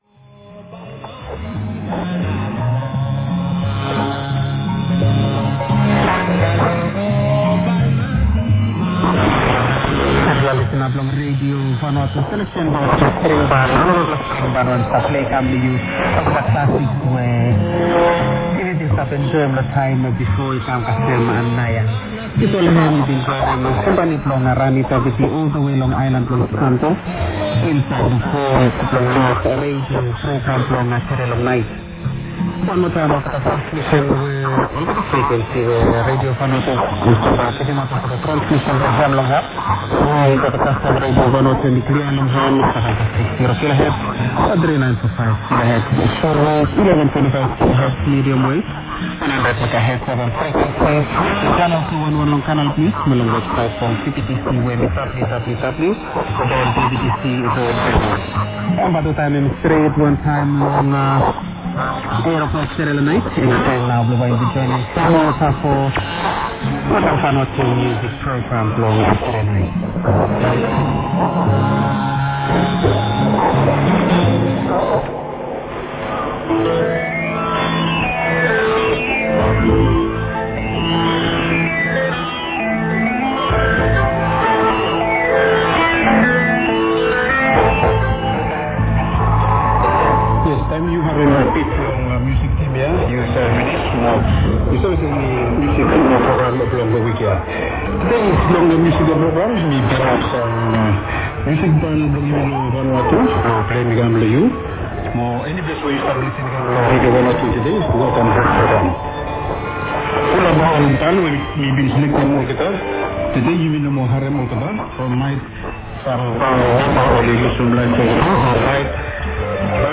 18時台は信号もピークで上サイドにパラオからの混信もありますが、バヌアツも信号が強く下に逃げるとクリアに聞こえます。
1830(バヌアツ時間Half past 8)前後のバヌアツ音楽が最高でした♪
・03:00-07:30 1829’00“-1833’30“ Half past 8 VUT前後 バヌアツ音楽♪
<受信地：東京都江東区新砂 東京湾荒川河口 RX:ICF-SW7600GR ANT:AN-12>
※05:00-05:04 女性SA「Radio Vanuatu Vois Blong Yumi」